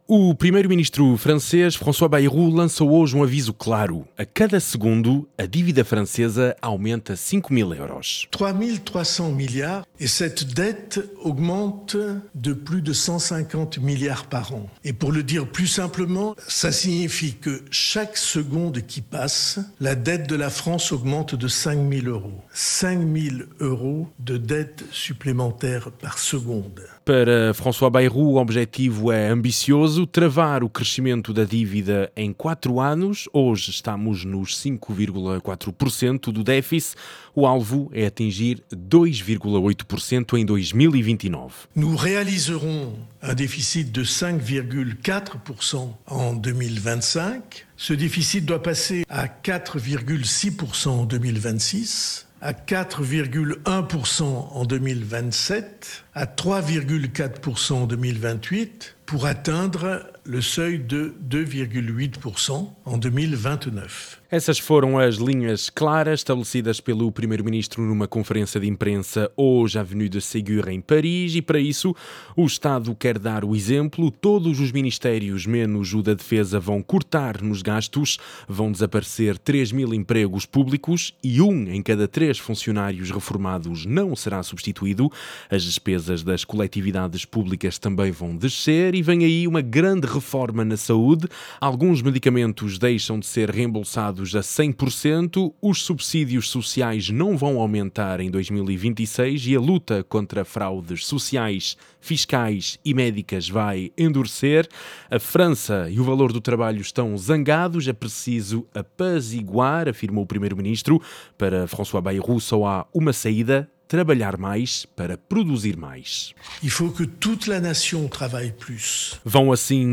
noticiário das 18h